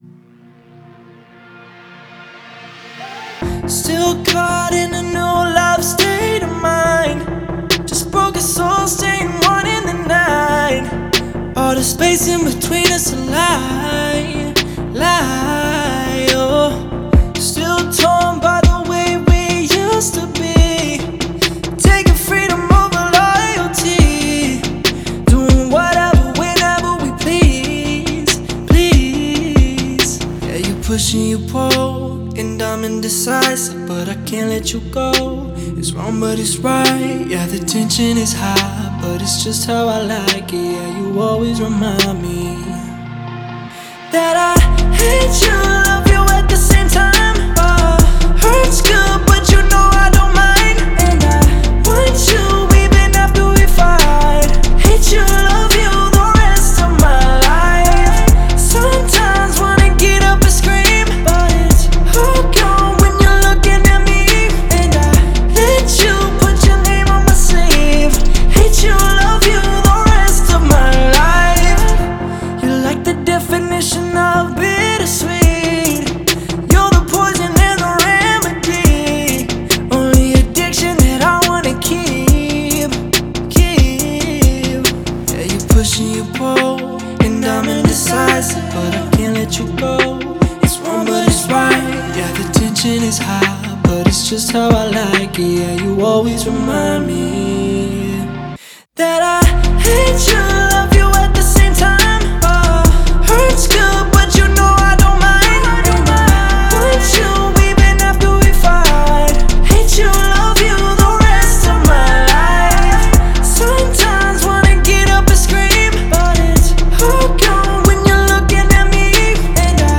энергичная поп-песня в стиле EDM